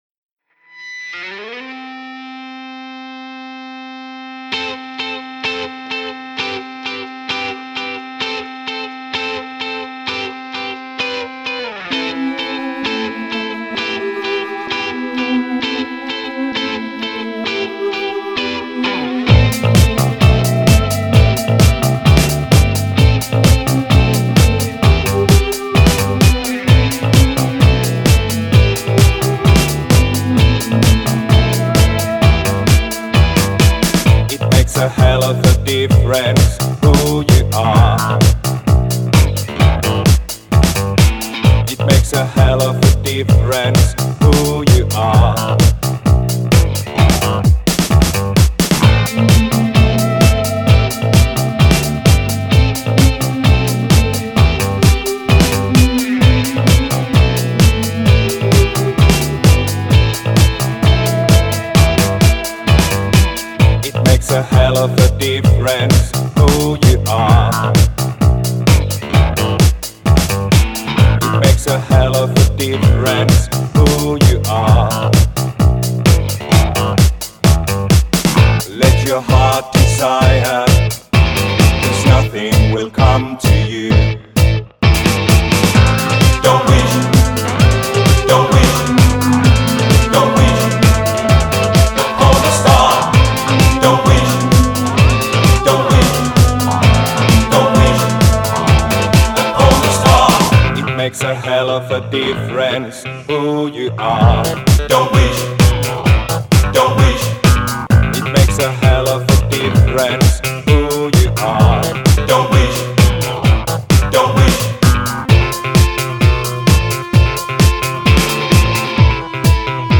goth/wave-y/funky synth-pop